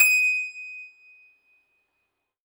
53s-pno22-D5.aif